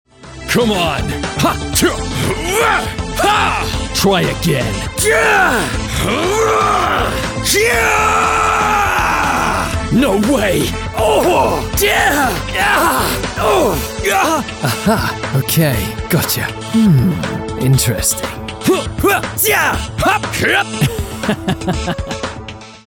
– Recorded in a sound-treated booth
I specialise in performing Voice overs in both American and British accents and dialects.